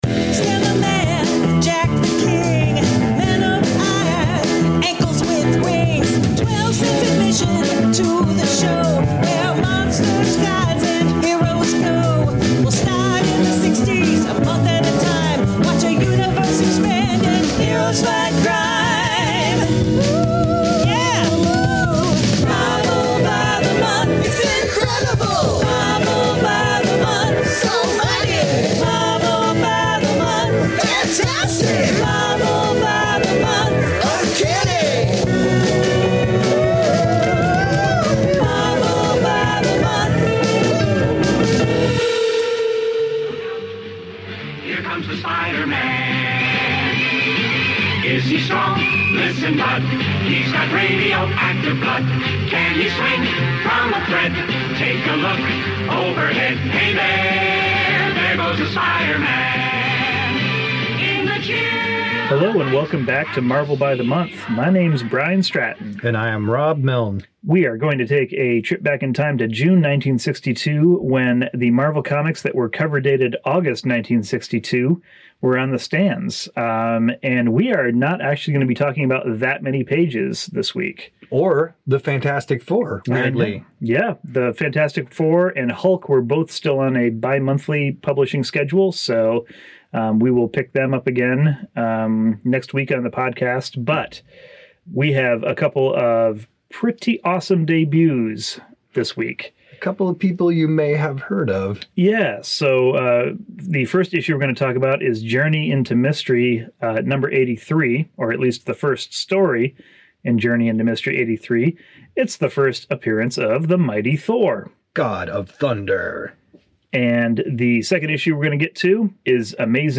All incidental music